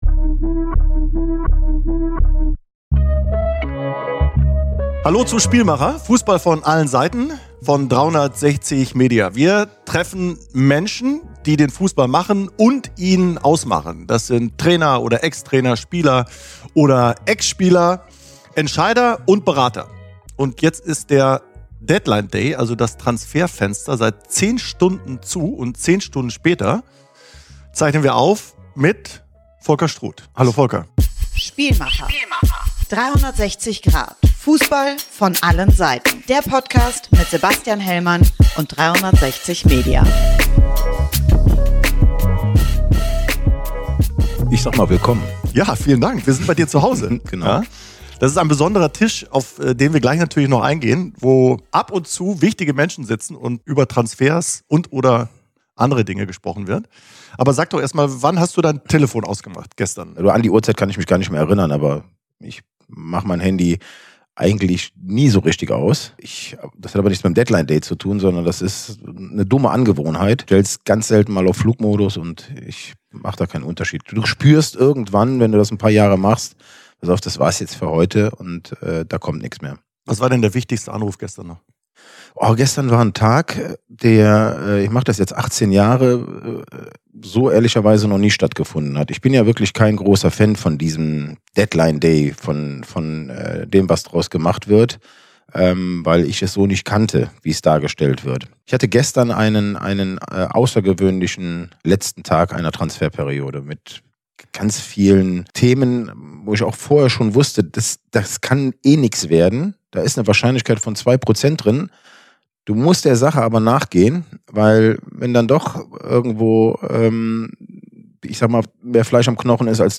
er nimmt Host Sebastian Hellmann mit in sein Esszimmer